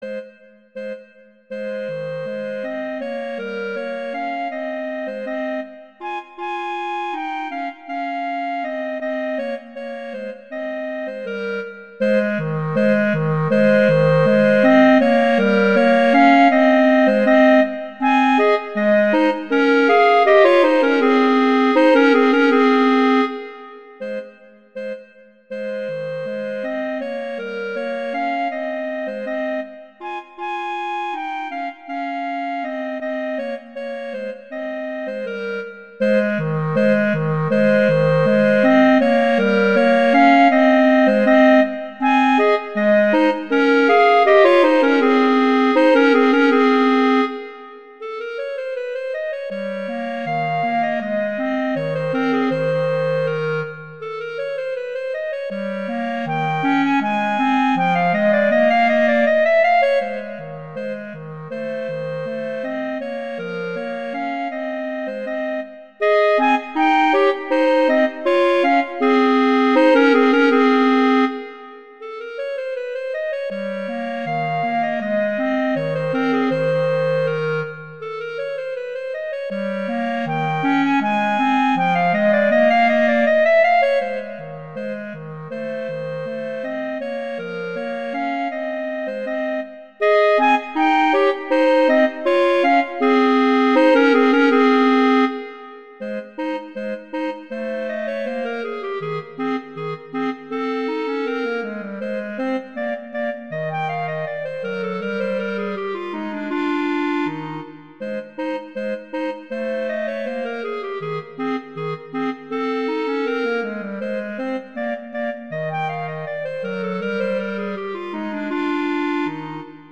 arrangements for two clarinets
classical